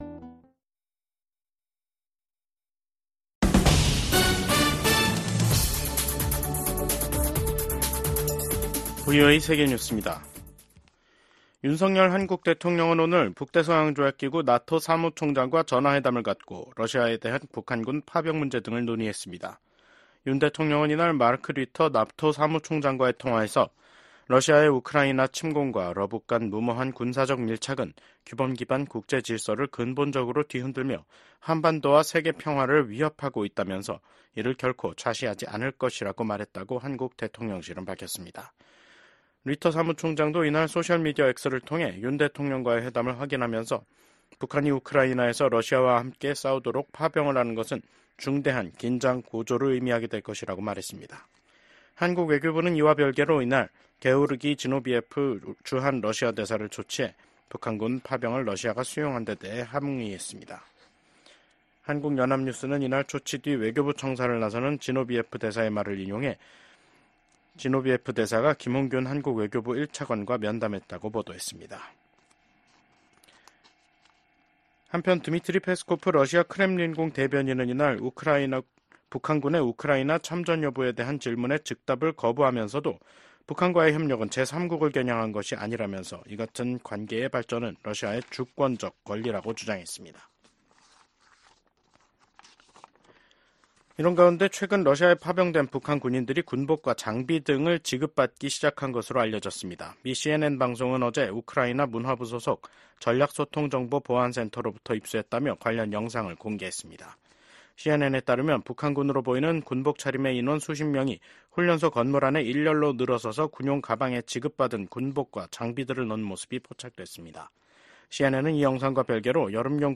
VOA 한국어 간판 뉴스 프로그램 '뉴스 투데이', 2024년 10월 21일 2부 방송입니다. 북한이 대규모 병력을 우크라이나 전장에 투입하기로 했다는 한국 정부의 발표에 대해 미국 정부가 중대한 우려의 입장을 밝혔습니다. 북한에 인력을 요청할 수밖에 없다면 이는 러시아의 절망의 신호일 것이라고 지적했습니다.